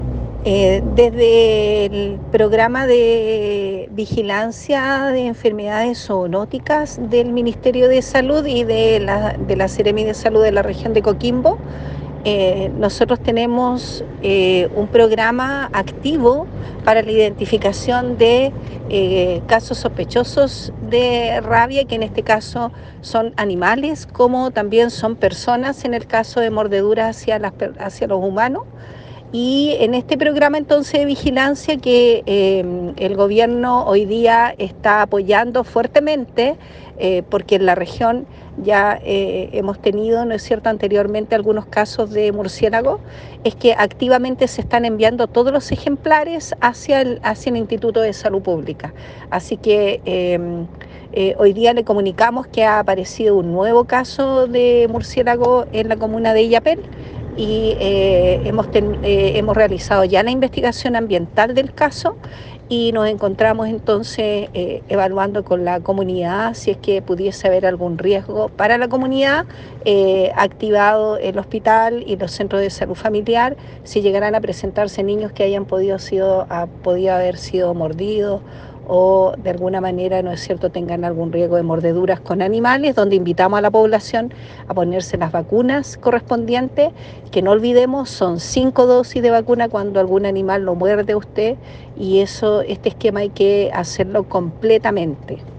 La Autoridad Sanitaria regional, Dra. Paola Salas, informó a la comunidad que